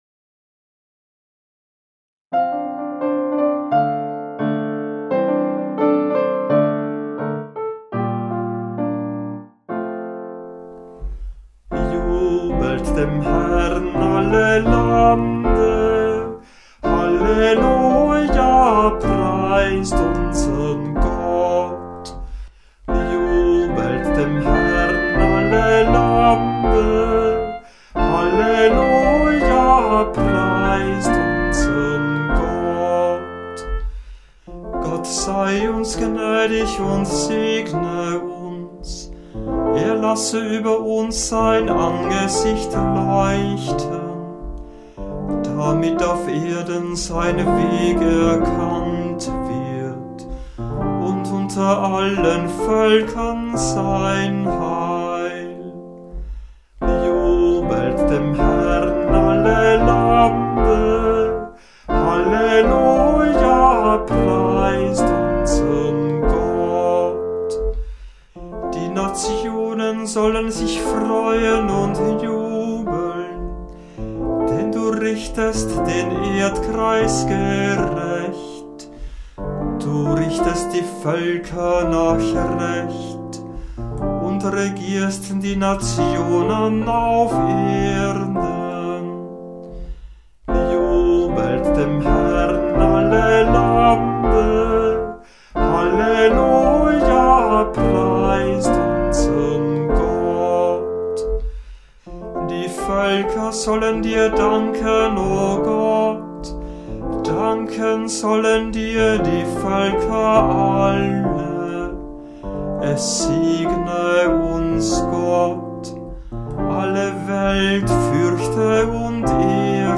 Hörbeispiele aus verschiedenen Kantorenbüchern
*) Kehrverse mit instrumentaler Zusatzstimme